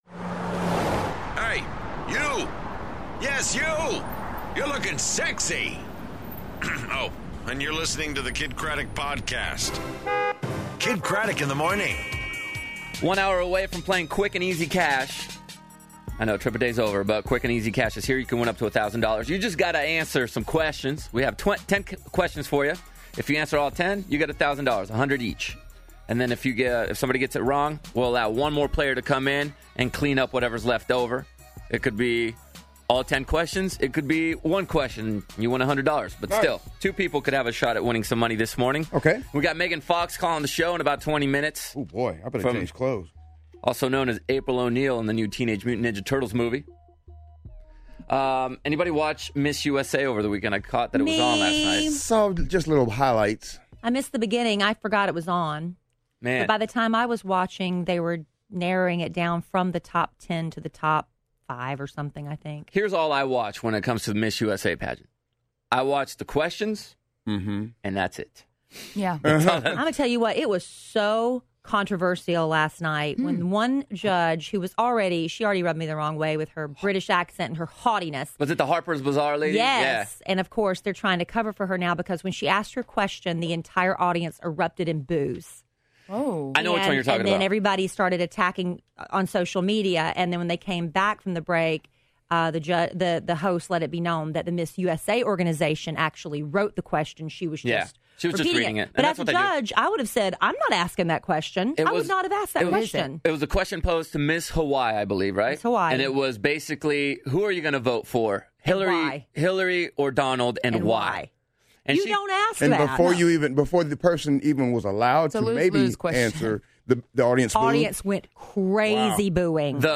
Who Did What This Weekend, Meghan Fox On The Phone, And Bride Requests...